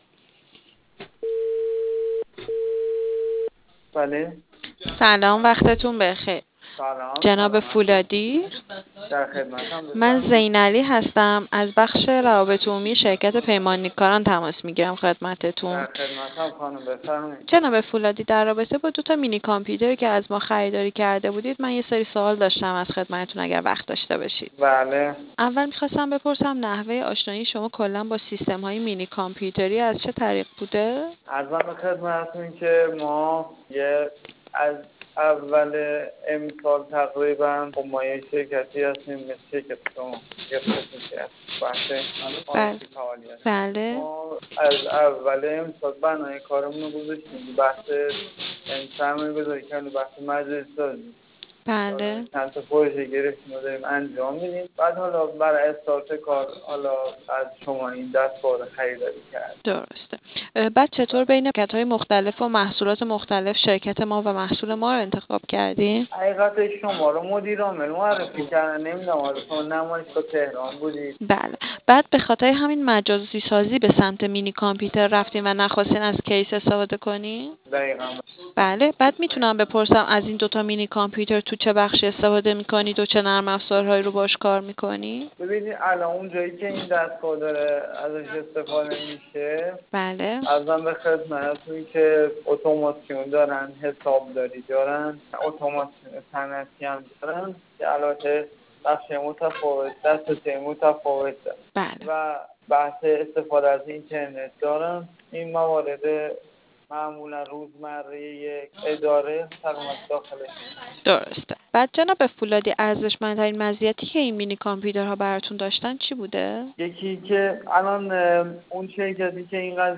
بدین منظور تعدادی مصاحبه با مشتریان عزیزمان که از مینی کامپیوتر استفاده کرده اند، گردآوری شده است.